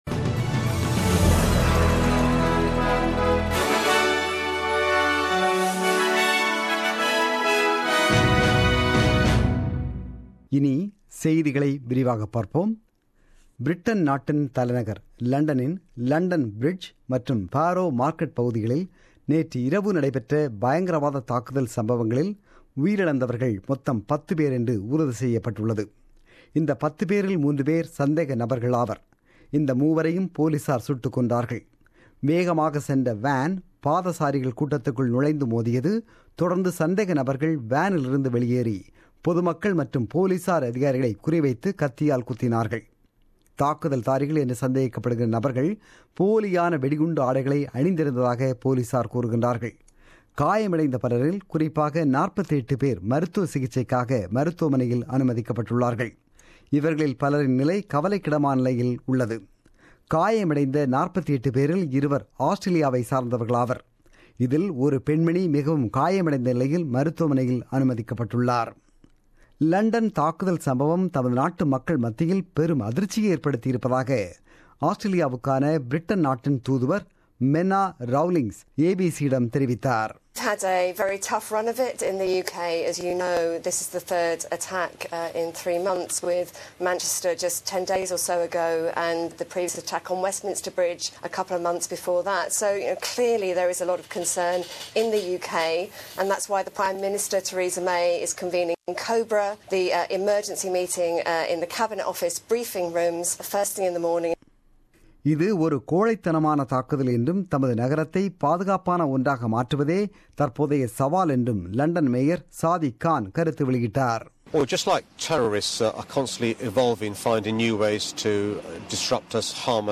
The news bulletin broadcasted on4 June 2017 at 8pm.